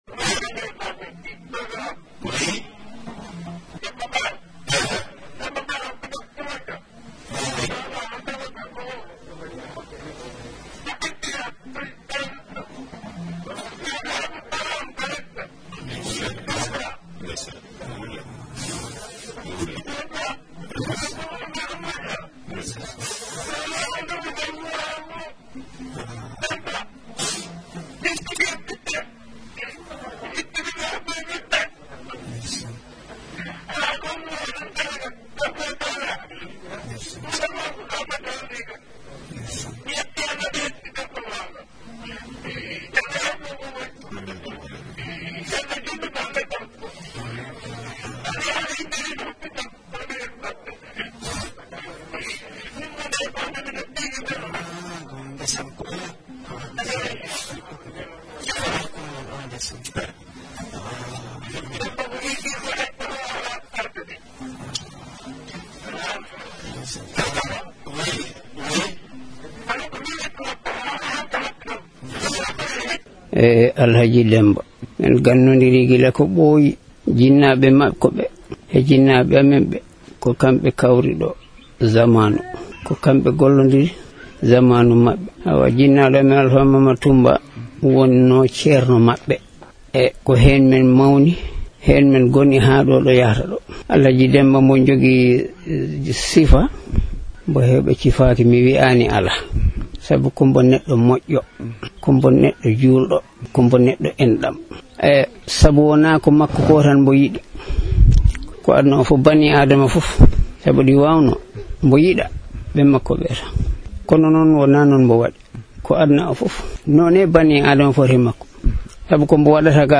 Voici les témoignages des fouladounabhés sur les œuvres de bienfaisance de ce patriote .Des témoignages parfois émouvants extraits de l’émission REPERES de ce dimanche 19 Janvier.